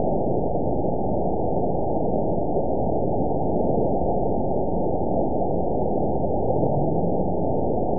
event 912249 date 03/22/22 time 05:57:19 GMT (3 years, 1 month ago) score 9.37 location TSS-AB02 detected by nrw target species NRW annotations +NRW Spectrogram: Frequency (kHz) vs. Time (s) audio not available .wav